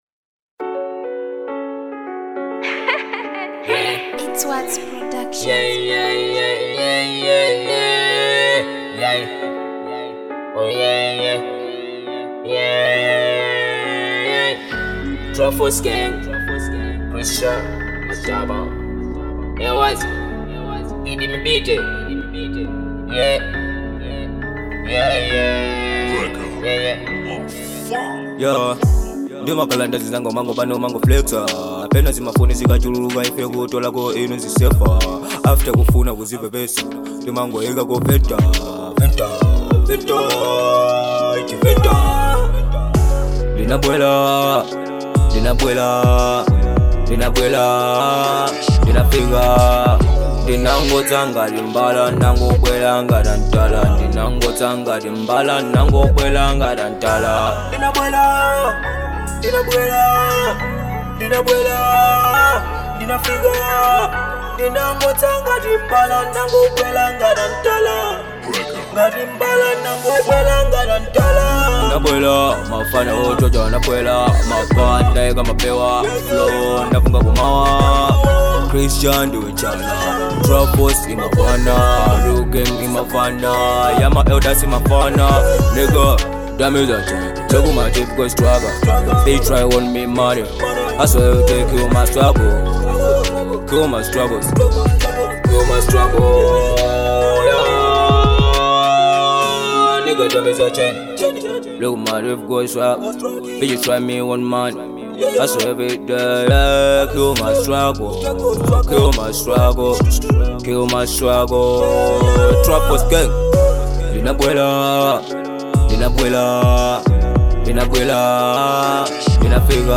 Genre : Trap